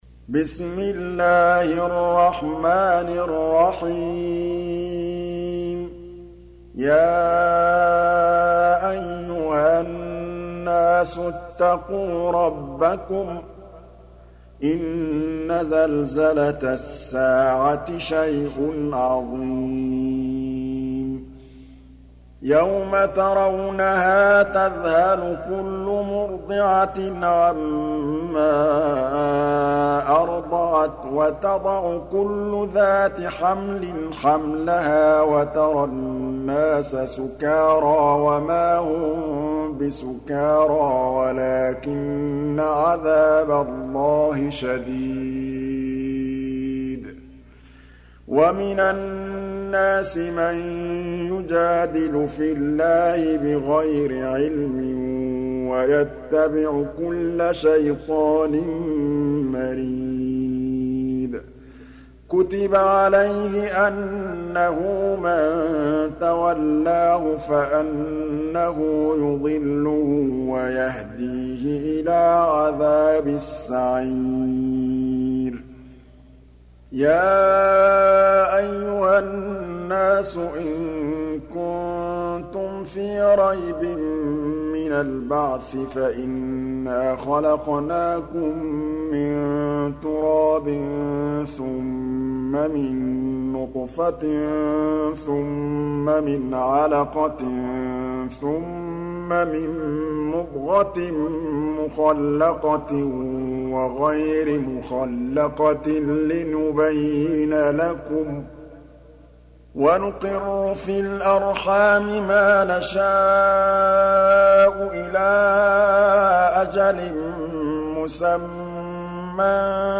Surah Al-Hajj سورة الحج Audio Quran Tarteel Recitation